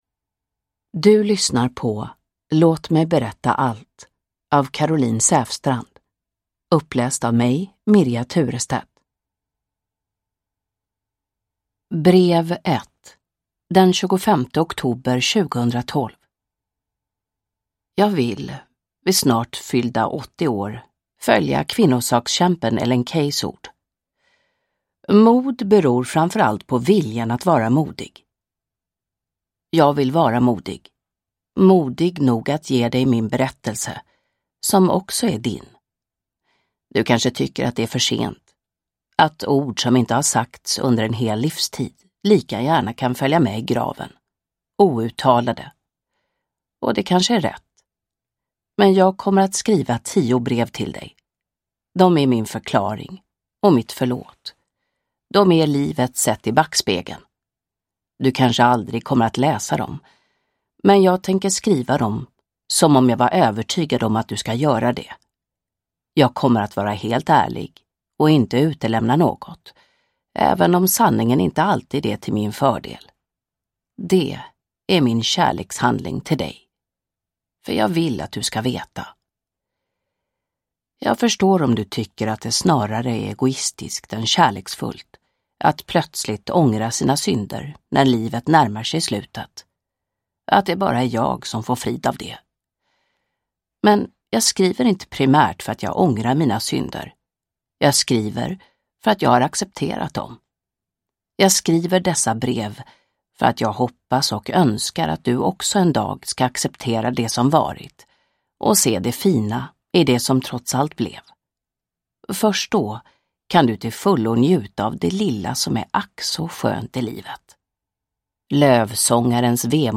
Låt mig berätta allt – Ljudbok – Laddas ner